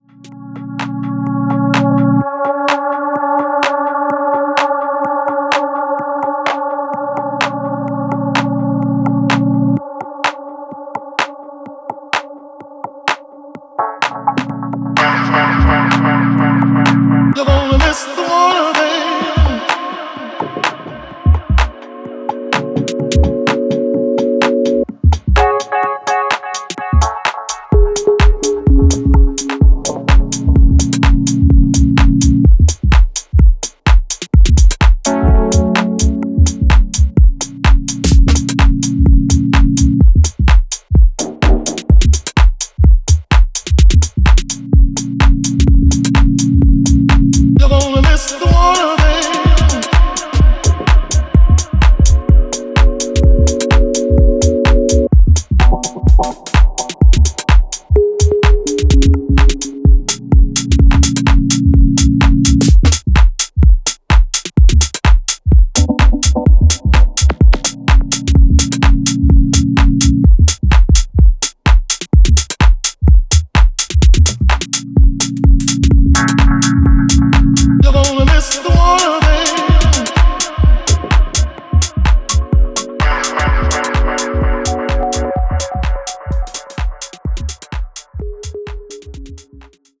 秀逸なミニマル・ハウス群を展開しています。